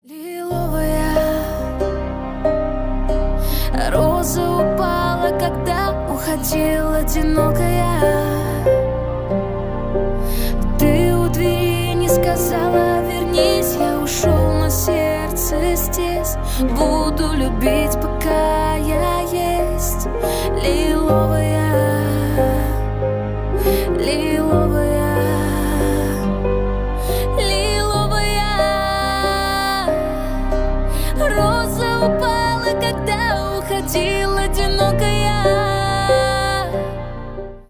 грустные , душевные
поп